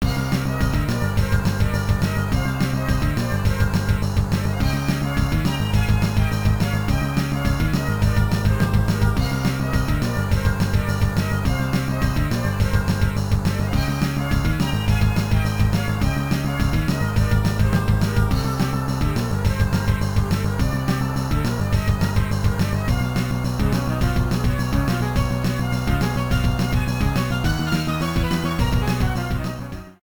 Self-recorded from emulator
Fair use music sample